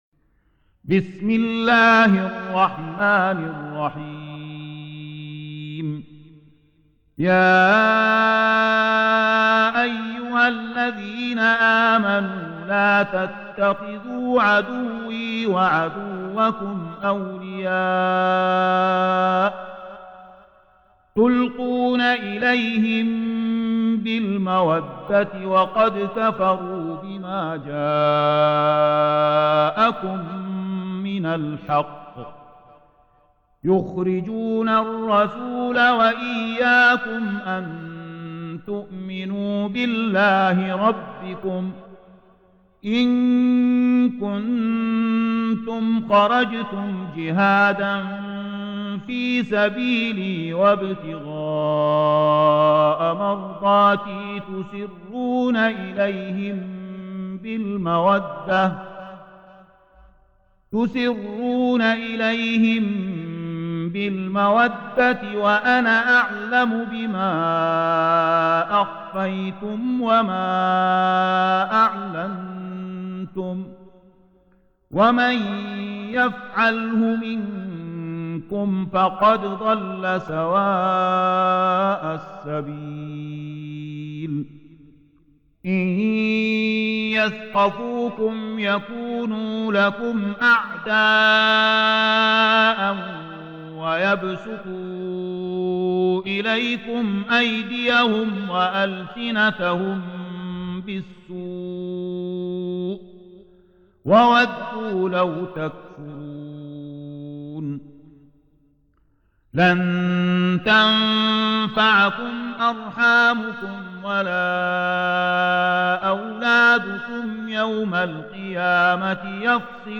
Surah Sequence تتابع السورة Download Surah حمّل السورة Reciting Murattalah Audio for 60. Surah Al-Mumtahinah سورة الممتحنة N.B *Surah Includes Al-Basmalah Reciters Sequents تتابع التلاوات Reciters Repeats تكرار التلاوات